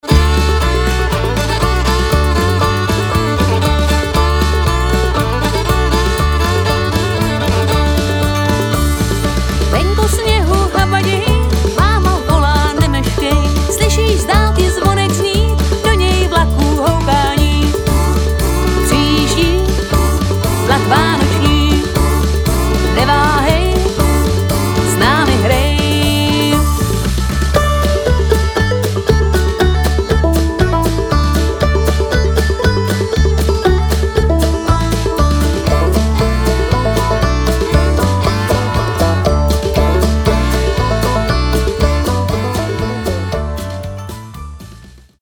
smyčcové kvarteto a pěvecký sbor.